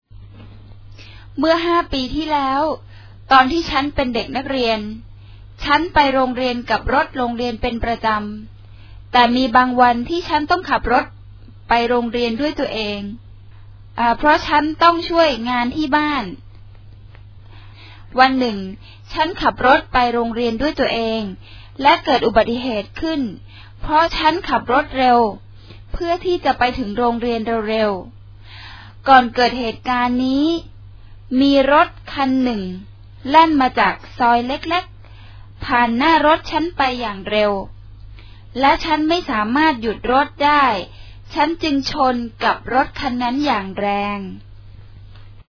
We have a staff of Thai who are proficient at making good recordings in mp3, perhaps in paragraph length chunks, and I will volunteer their services and make the product available if anyone can provide some good stuff--stories of everyday life written in Thai with a fairly literal english translation.